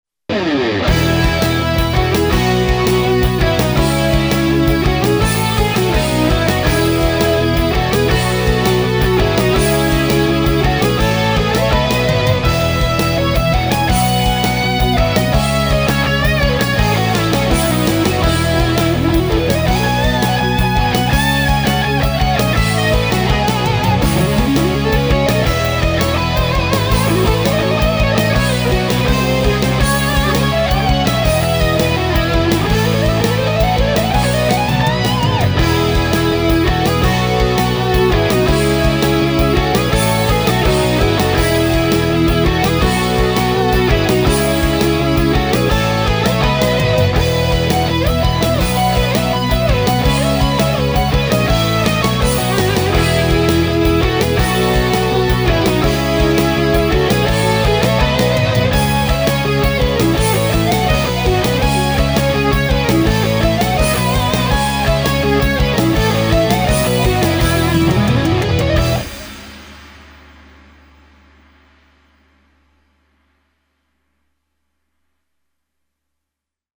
Advice/comments on the mix for this short instrumental rock song, "Roadstar"
It was recorded on a Tascam 2488neo, and then brought to Sound Forge Pro 10 where the preset "Smooth Compression" was applied, from the Wave Hammer window, just to add volume.
The drum machine track was done on a Boss DR-770; any advice on how to get that sounding better would be appreciated as well. Track layout is quite simple; One stereo drum machine track (audience perspective) Two rhythm guitar tracks (distorted) one panned hard left, one panned hard right (two separate takes/performances/tones) One mono bass guitar track (centered) One stereo lead guitar track (centered) One stereo lead harmony guitar track (panned slightly off-center) One underlying stereo keyboard track (low in the mix intentionally) Any help or advice on the mix would be GREATLY appreciated as I want to send final mixes to get mastered very soon.